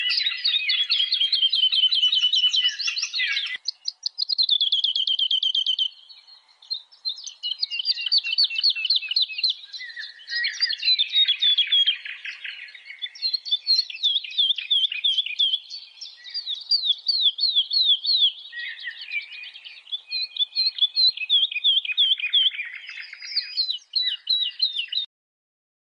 凤头百灵鸟叫声